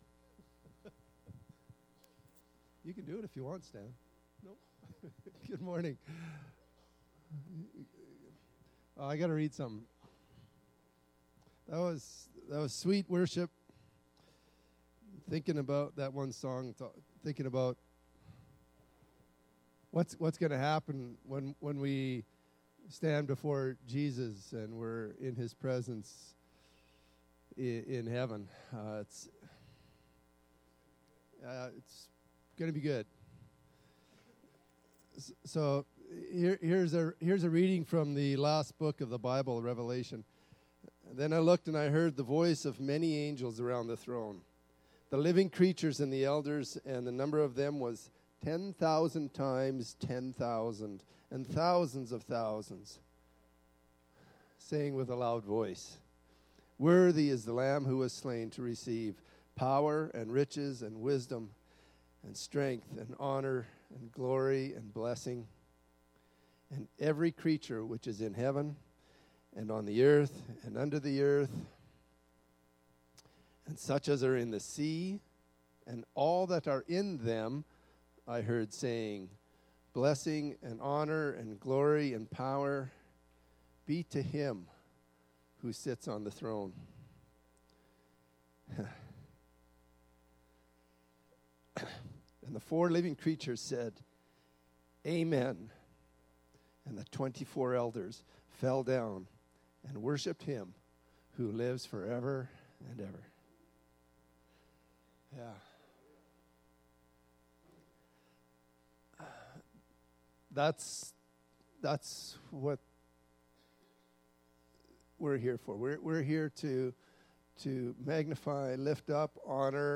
– Jesus will what even if it takes months or years Jesus will what for you to keep following him. click here to listen to sermons